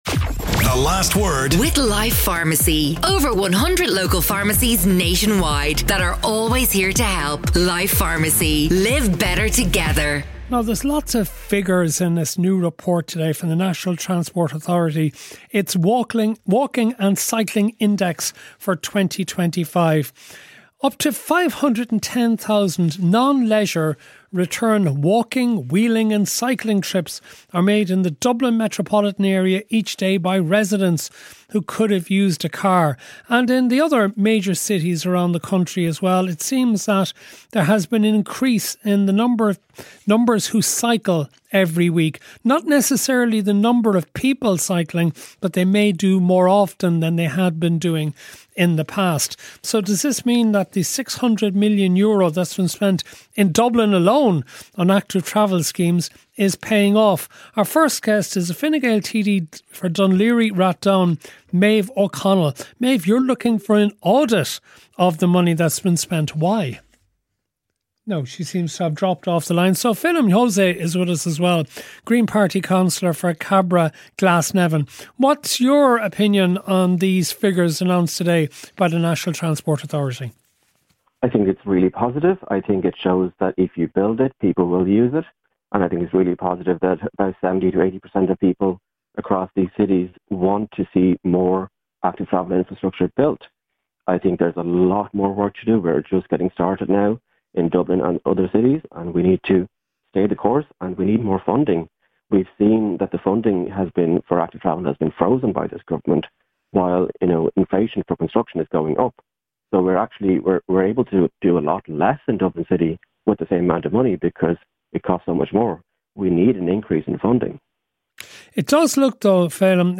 Feljin Jose, Green Party Councillor and Maeve O’Connell, Fine Gael TD, speak to Matt on The Last Word.